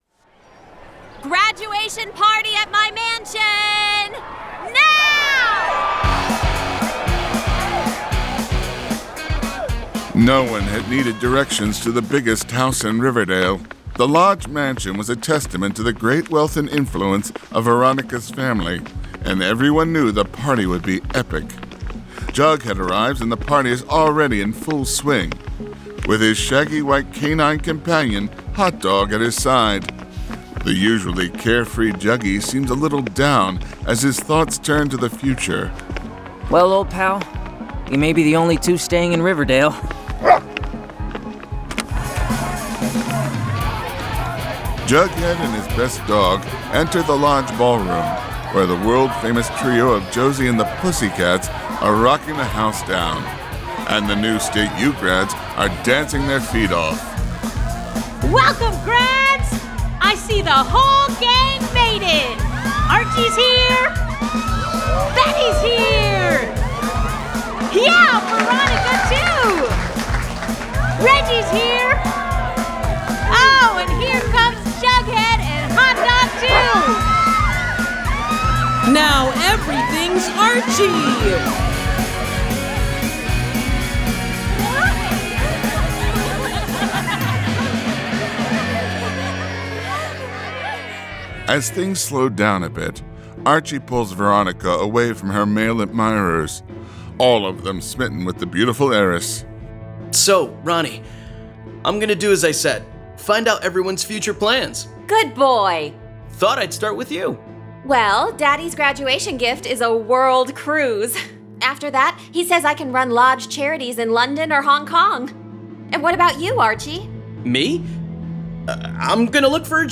Full Cast. Cinematic Music. Sound Effects.
[Dramatized Adaptation]
Adapted from the graphic novel and produced with a full cast of actors, immersive sound effects and cinematic music.